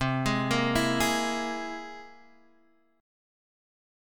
C+7 chord